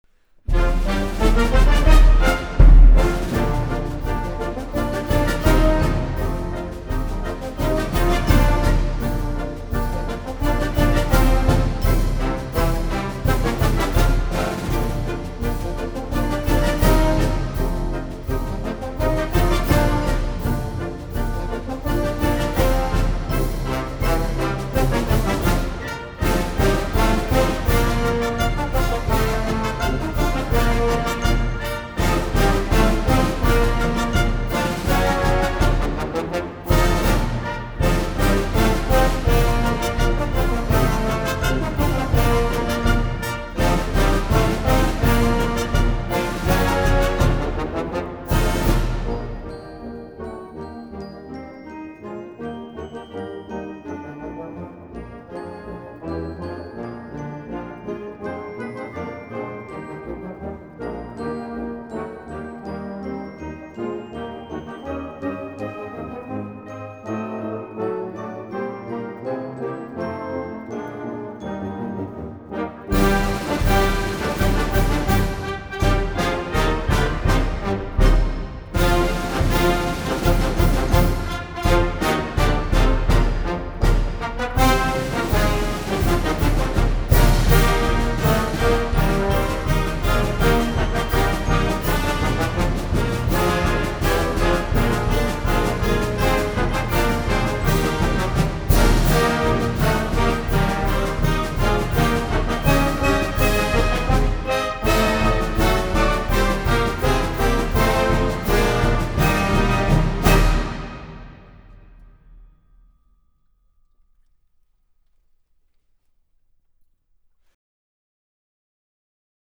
这是一张不吵的进行曲录音，它虽然没有办法换下您家中的1812炮声，